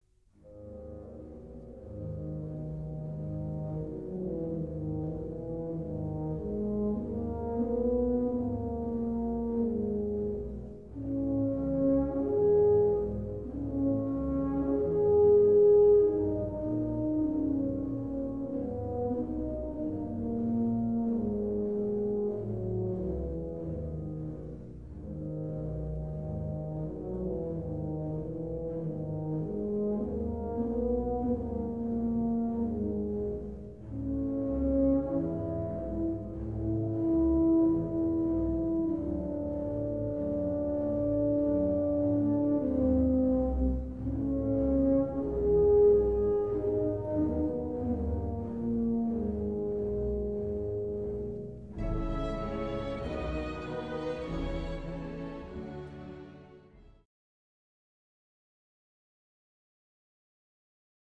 Ce qui caractérise la famille des cuivres, ce n'est pas le matériau, mais le fait qu'ils partagent le même type d'embouchure.
le tuba, Moussorgsky, Bydlo